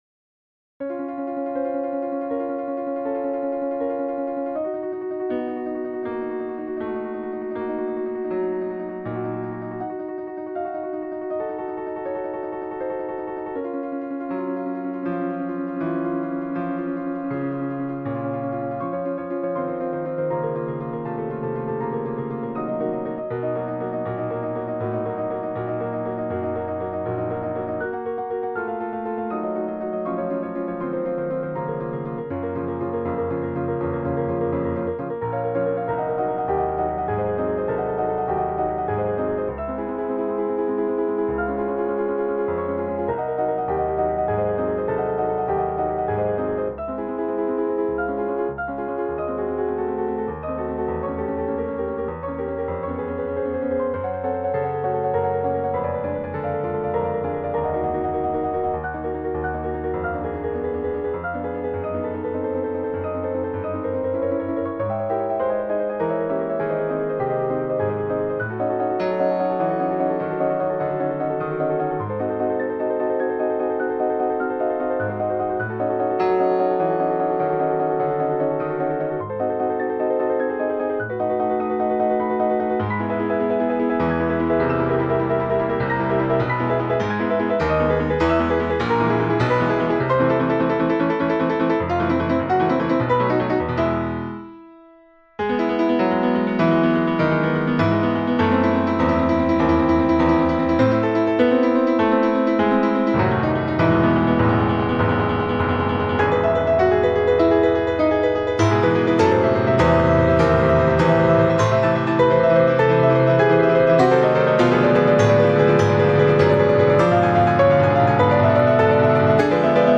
نت پیانو : اتود شماره 12 فرانتس لیست